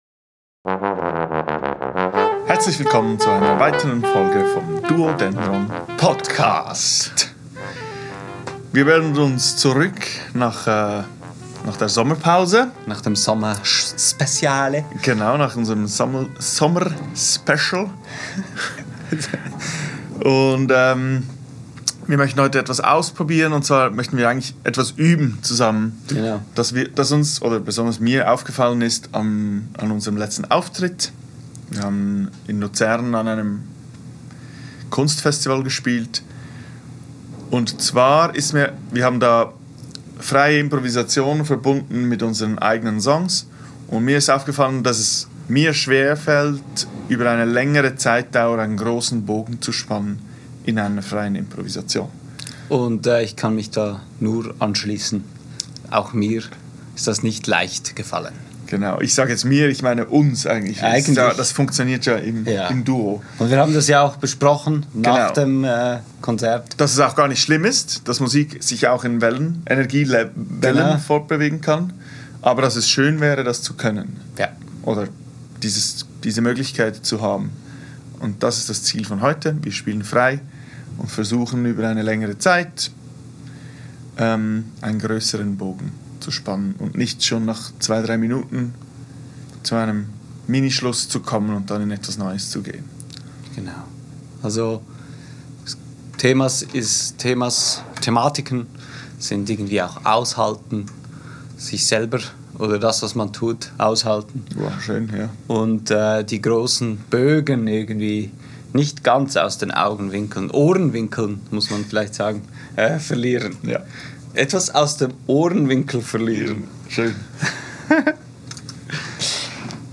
Beschreibung vor 1 Jahr In der heutigen Folge beschäftigen wir uns mit grösseren Bögen in der freien Improvisation. Das beinhaltet auch das Aushalten und Akzeptieren von dem Material das man gerade spielt.
Aufgenommen am 20.08.2024 im Atelier